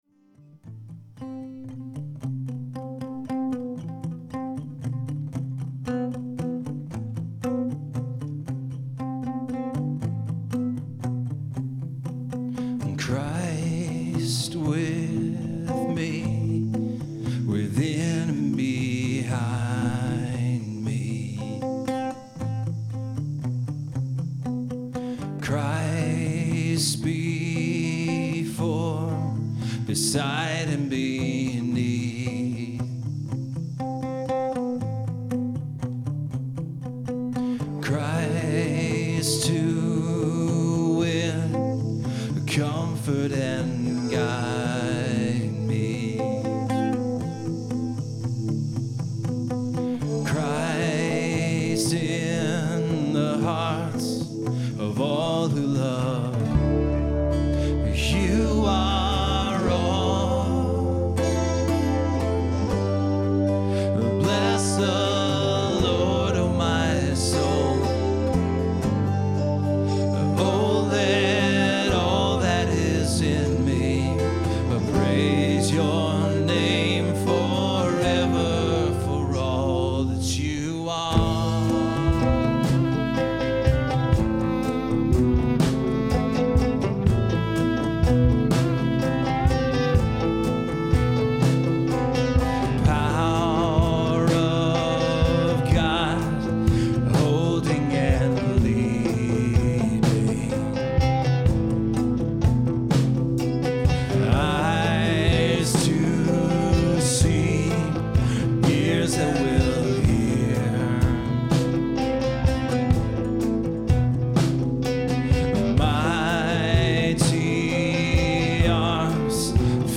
Performed live at Terra Nova - Troy on 3/14/10.